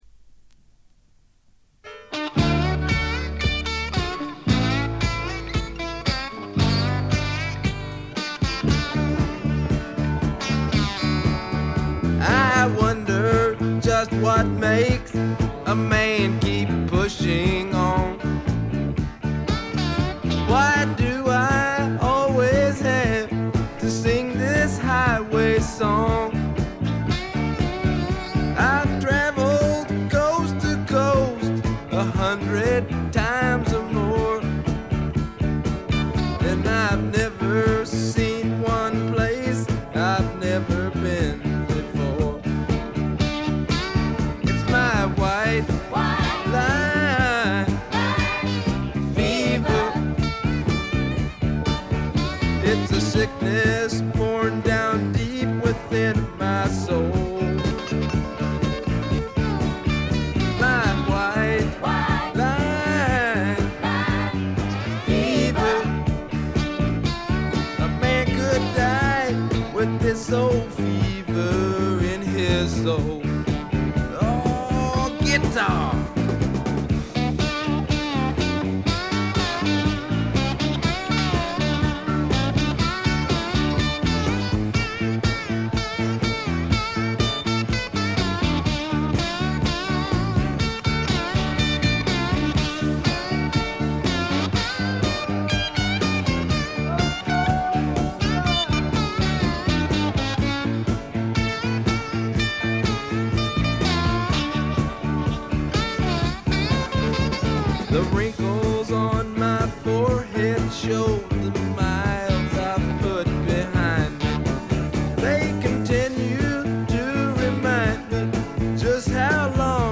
ほとんどノイズ感無し。
いうまでもなく米国スワンプ基本中の基本。
試聴曲は現品からの取り込み音源です。